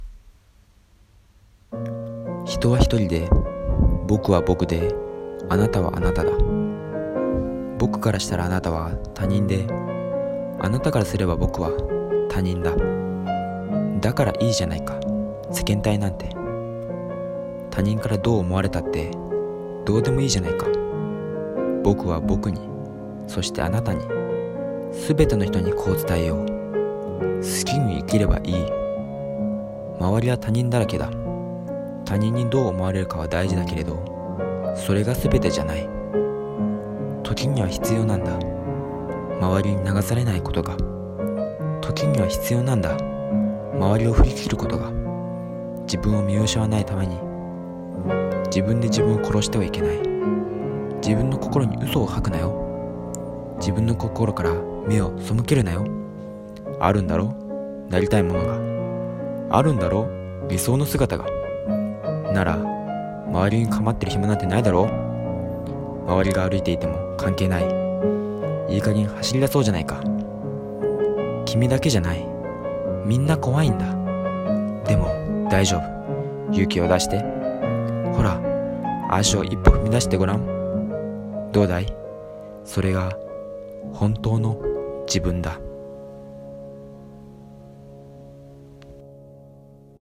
【一人朗読】他人と自分【一人声劇】